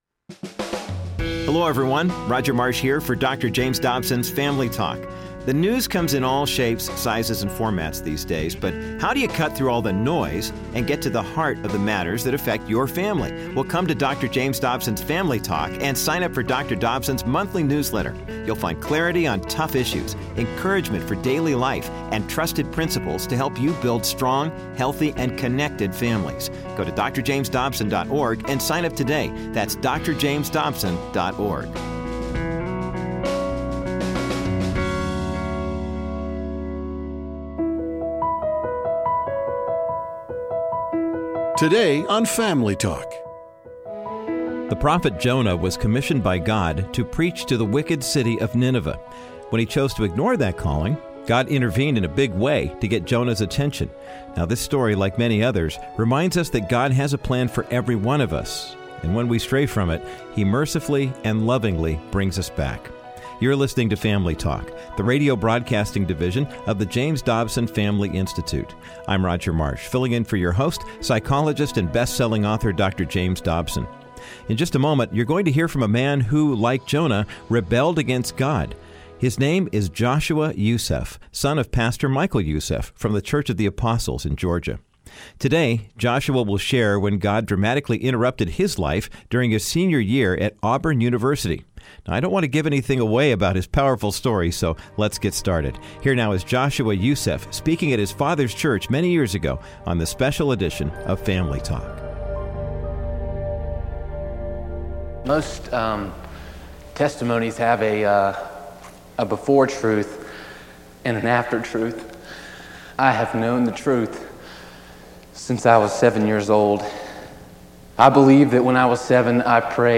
Host Dr. James Dobson
Testimony